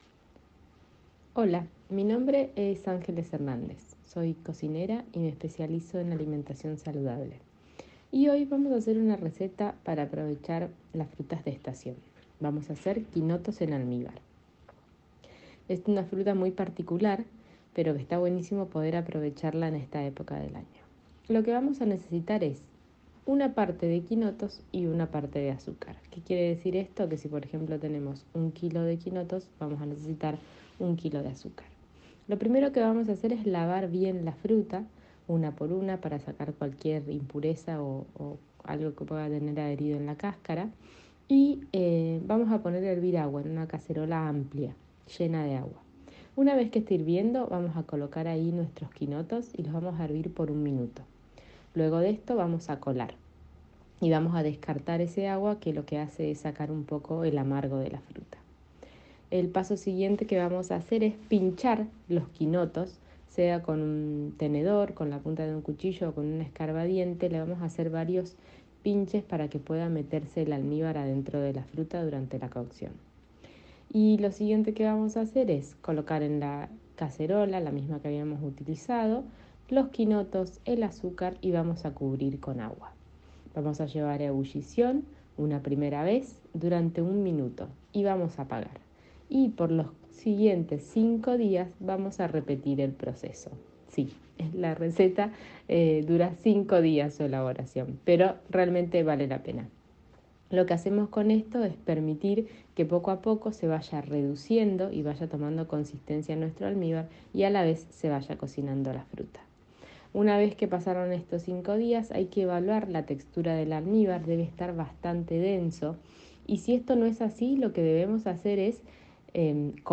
Audio receta de los quinotos en almíbar